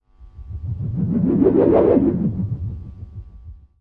Звуки бумеранга - скачать и слушать онлайн бесплатно в mp3